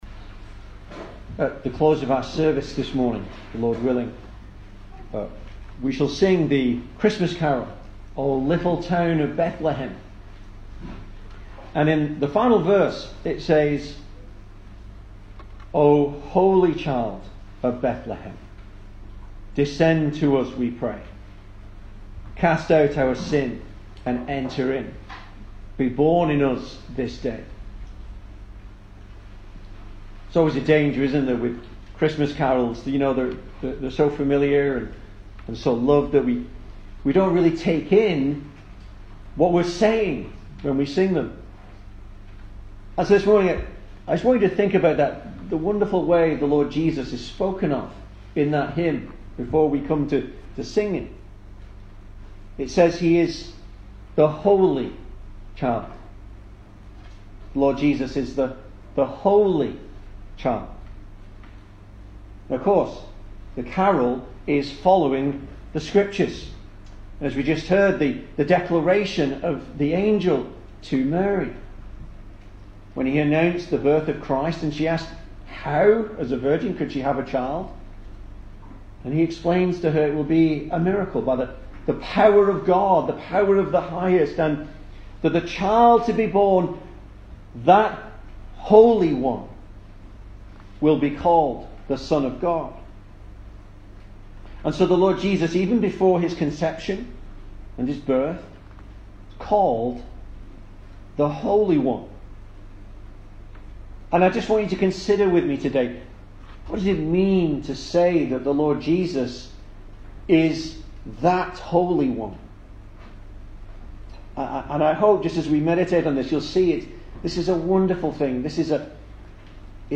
Service Type: Sunday Morning
Christmas Sermons